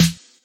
shdnbf_snr.wav